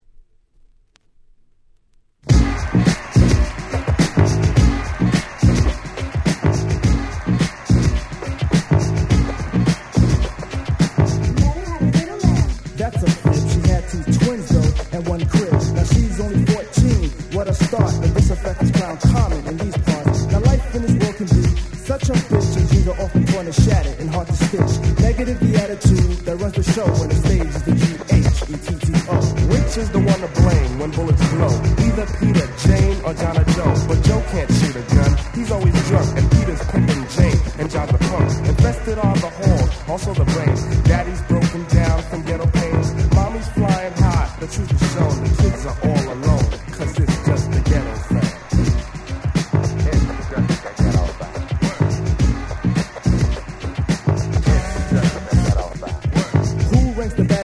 89' Smash Hit Hip Hop !!